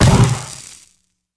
rifle_hit_card2.wav